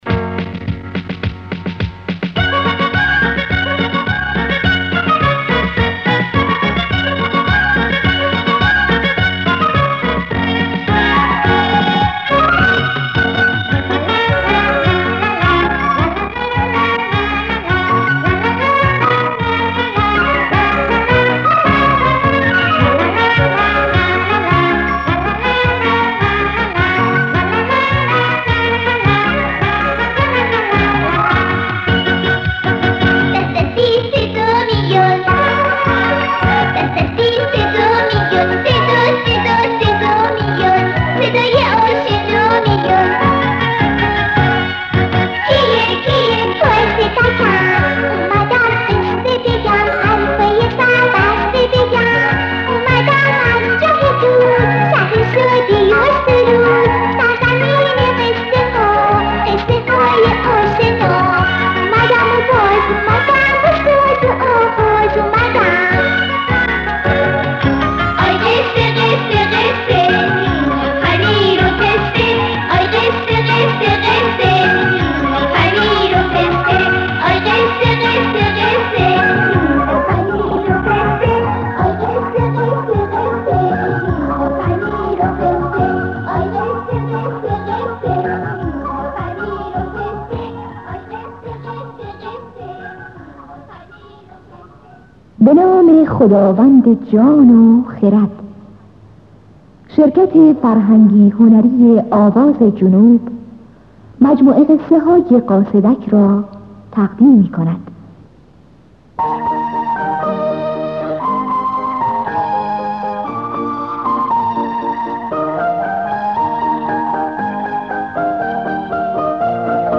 قصه کودکانه صوتی حسن کچل
قصه-صوتی-کودکانه-حسن-کچل-بخش-اول-1.mp3